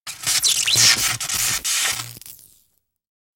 electricity_high_voltage_spark
Tags: Sci Fi Play